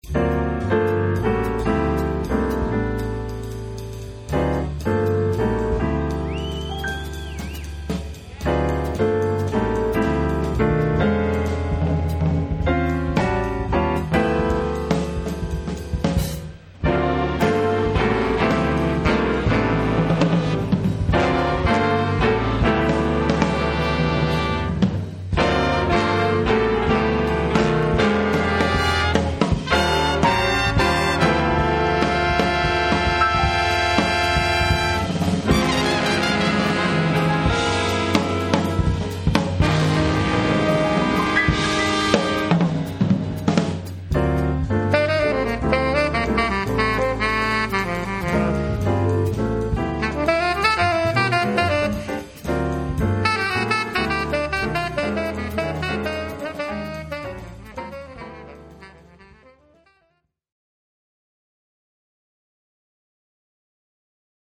Recorded at the Chicago Jazz Festival,